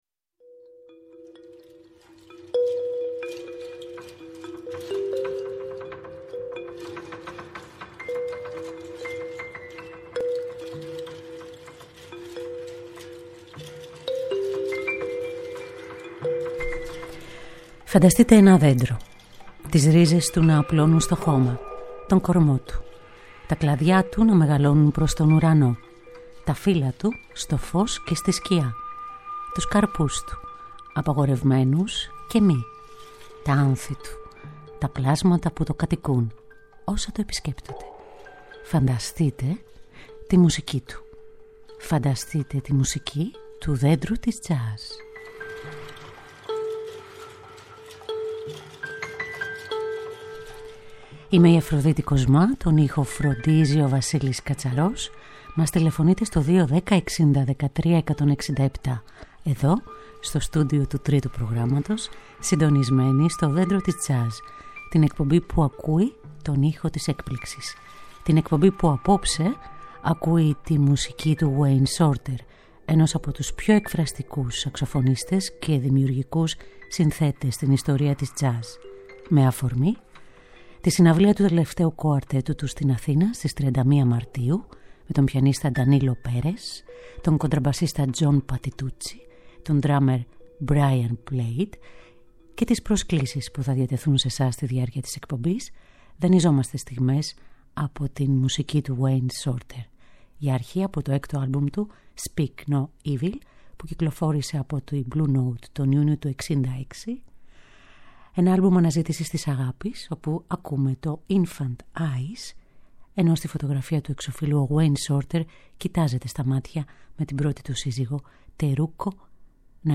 Μουσική Τζαζ